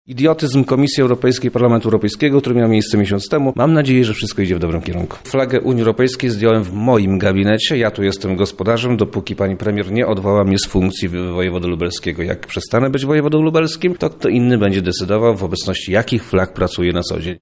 – tłumaczy wojewoda Przemysław Czarnek